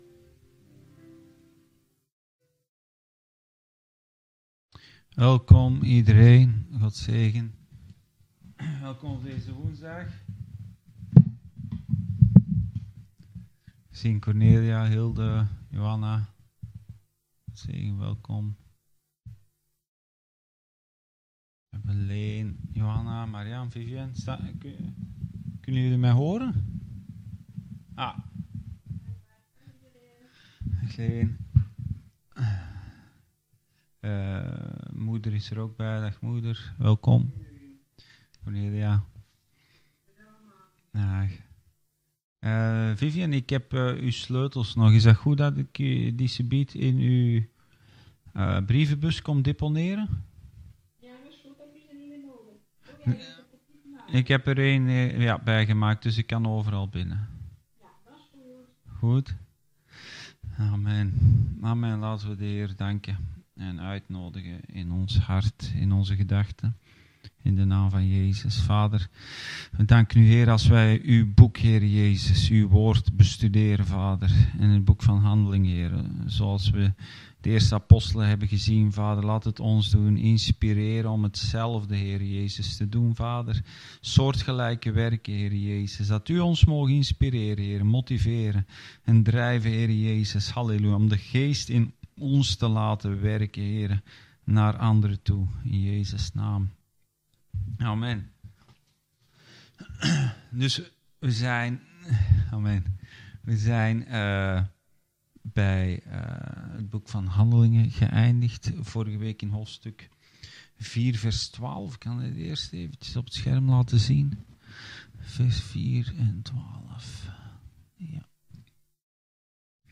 Bijbelstudie: handelingen 4-5